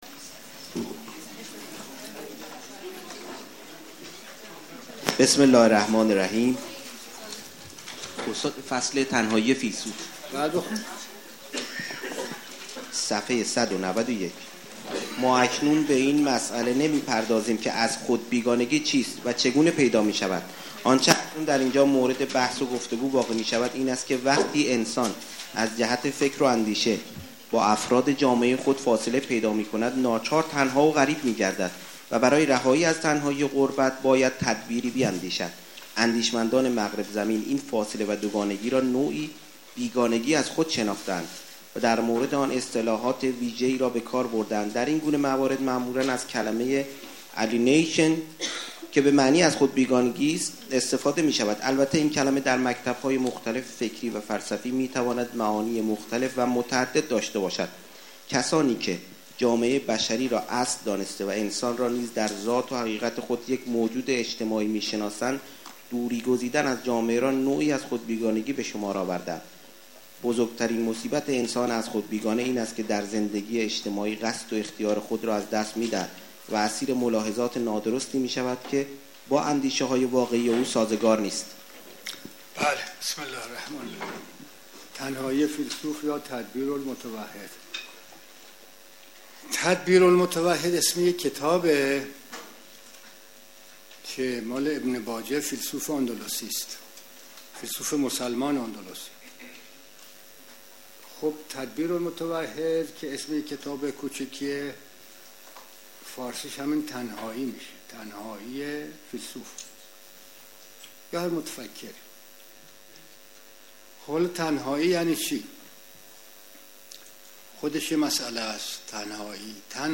در کلاس درس استاد دکتر دینانی در این فایل به باز کردن این بحث می پردازد.